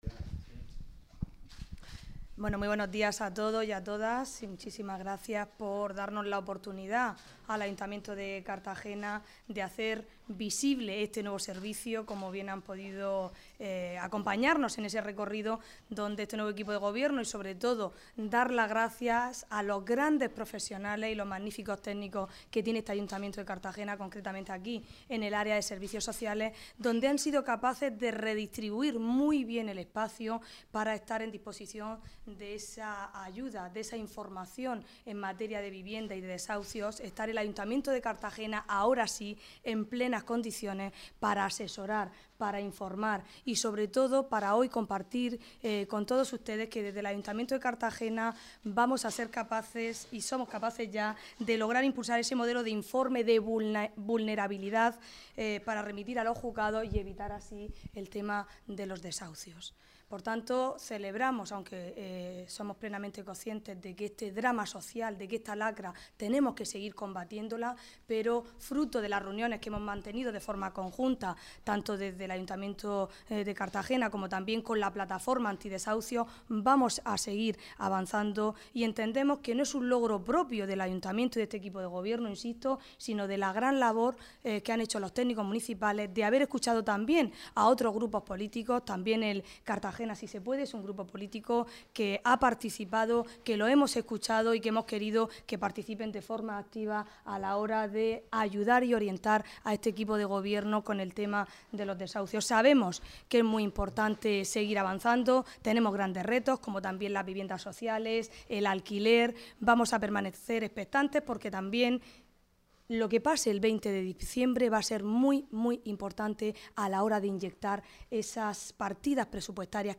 Audio: 2015-12-15 Declaraciones de la vicealcaldesa a la oficina antidesahucios (MP3 - 9,34 MB)